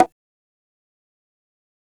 Metro Kongo Perc.wav